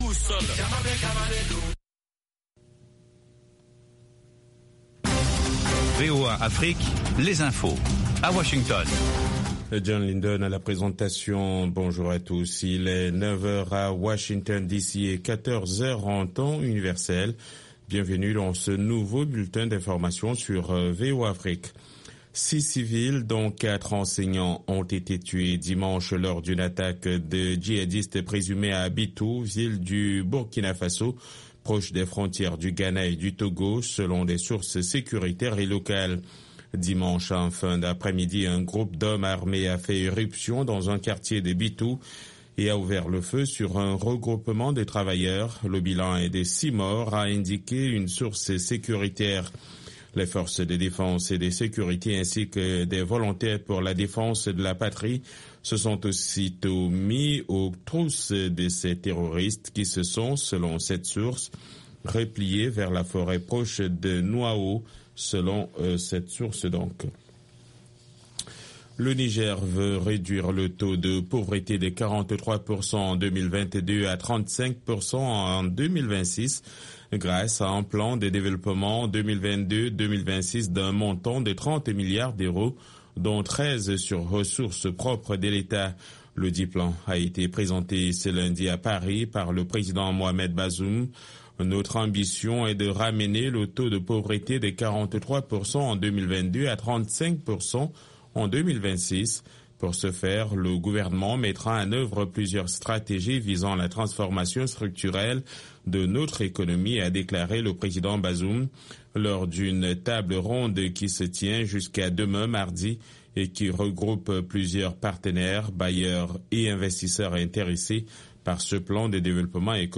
5 min Newscast